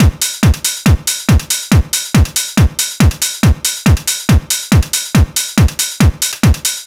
NRG 4 On The Floor 004.wav